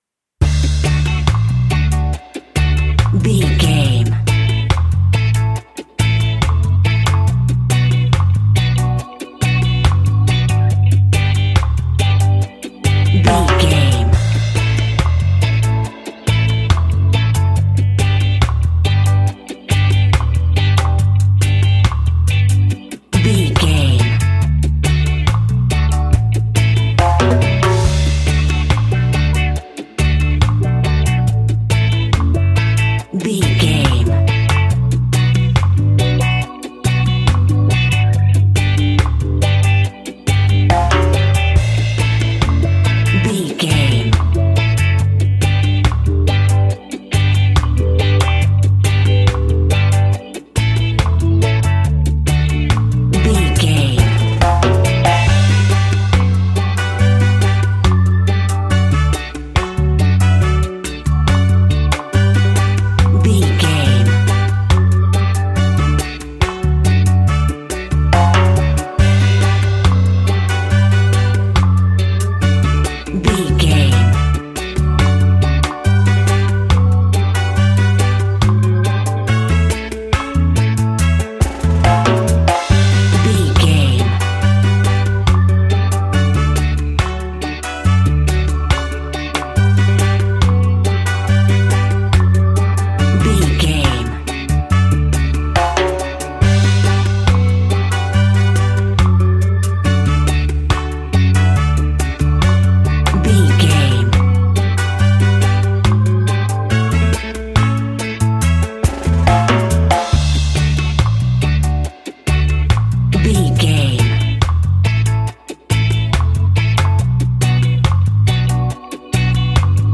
Classic reggae music with that skank bounce reggae feeling.
Ionian/Major
Slow
reggae instrumentals
laid back
chilled
off beat
drums
skank guitar
hammond organ
percussion
horns